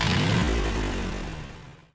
boatengine_stop.wav